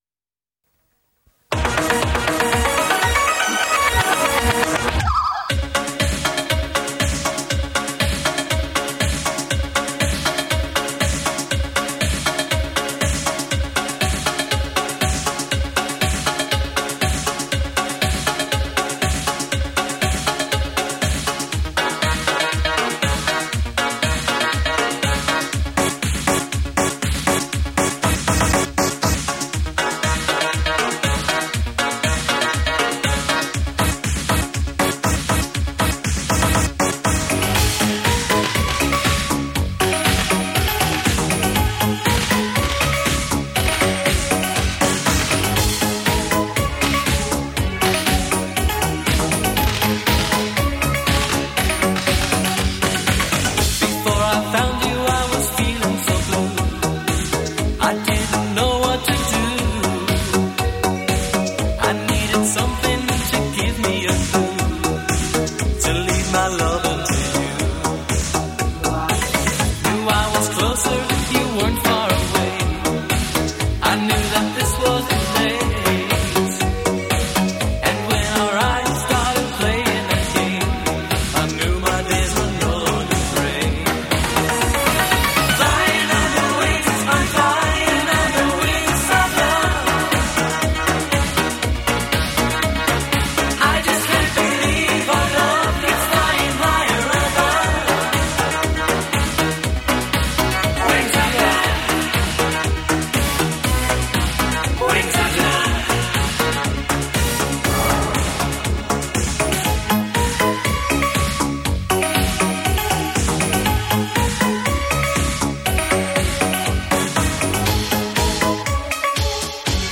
舞中之王中旋风，超动感挑战节奏！